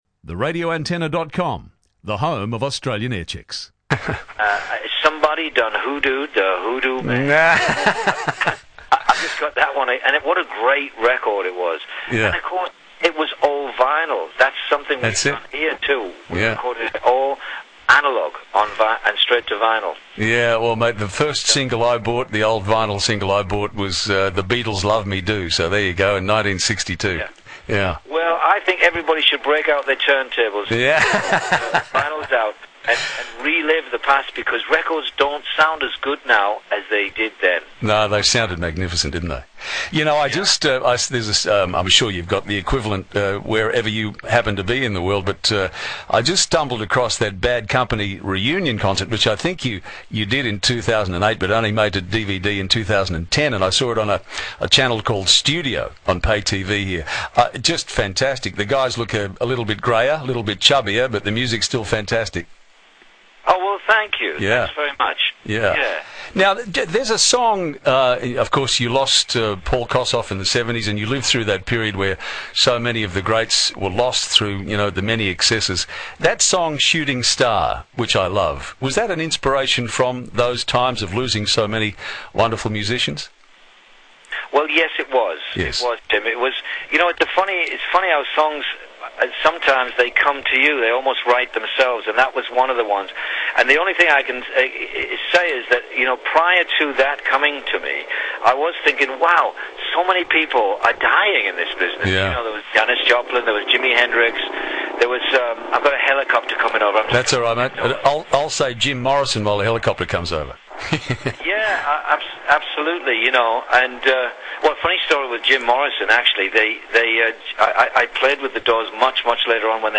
2UE Tim Webster chats to Paul Rodgers 220114